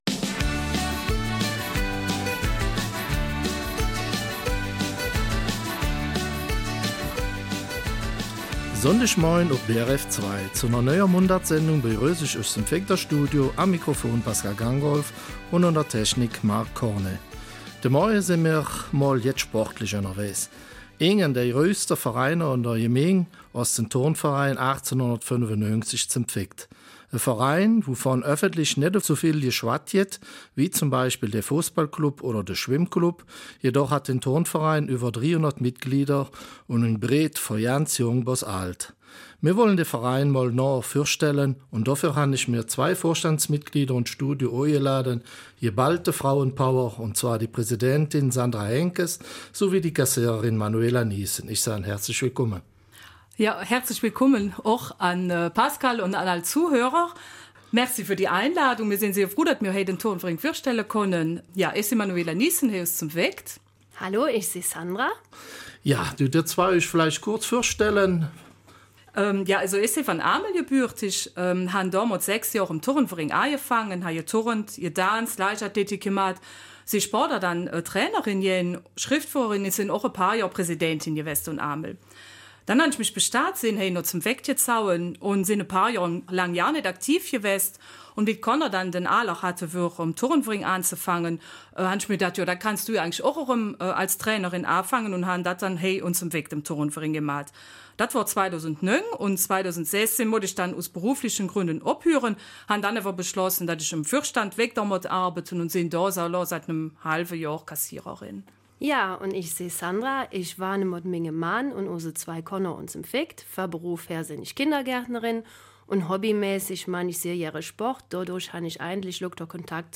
Eifeler Mundart: Turnverein 1895 St.Vith